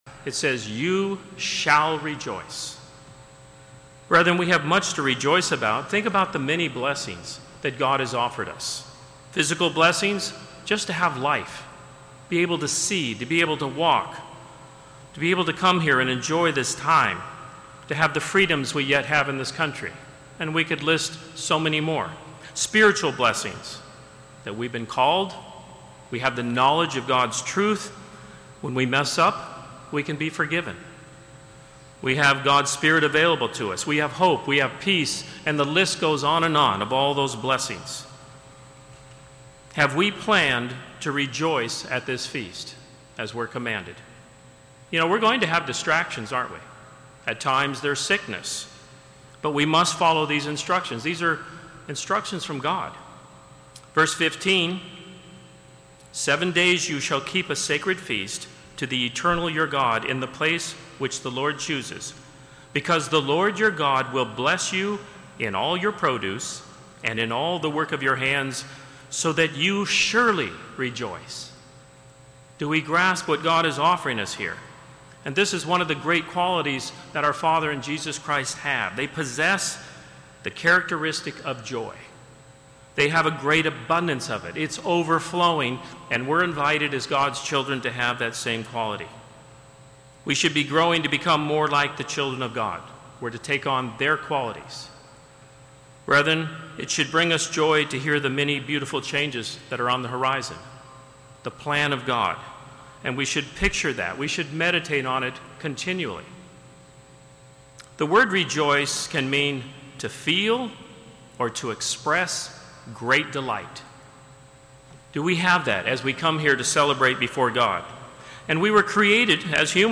This sermon was given at the Daytona Beach, Florida 2021 Feast site.